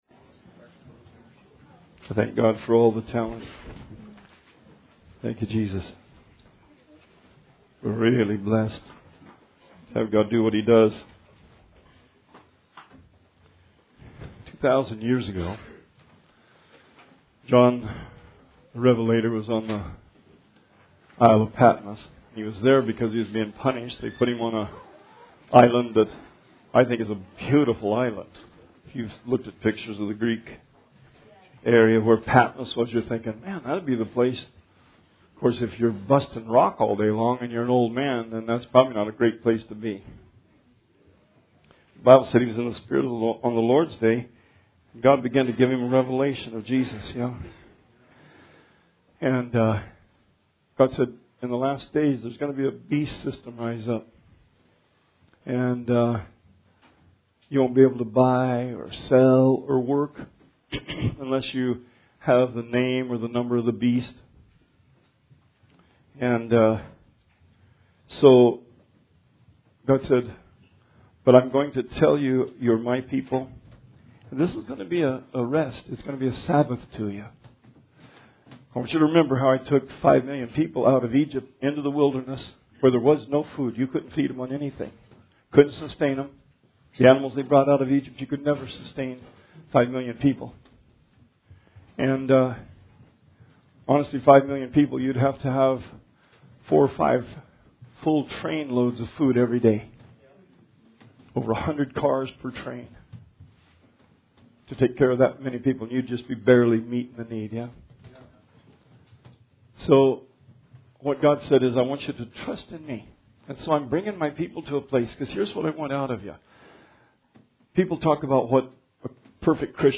Sermon 2/2/20